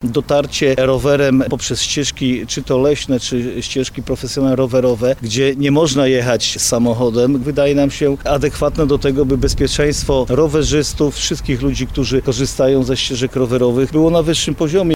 – Rowery mają zapewnić bezpieczeństwo rowerzystom w naszym regionie – mówi marszałek województwa lubelskiego, Jarosław Stawiarski.